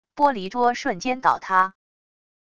玻璃桌瞬间倒塌wav音频